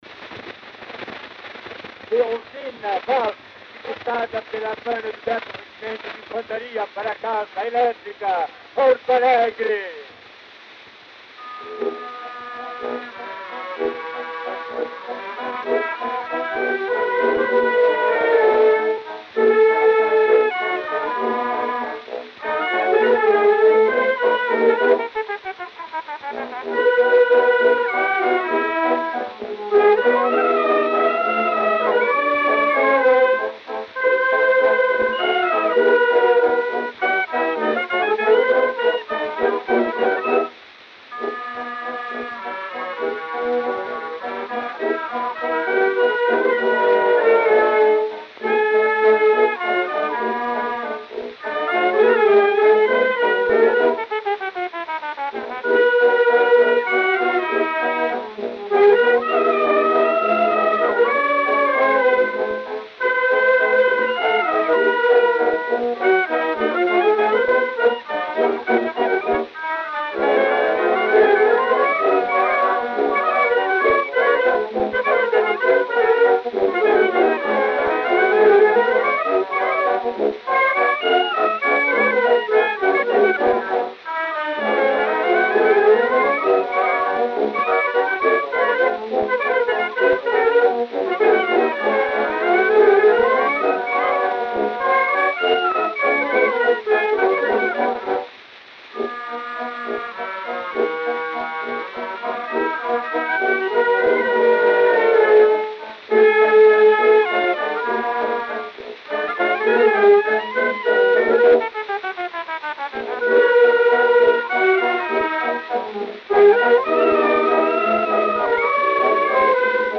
O gênero musical foi descrito como "Valsa" e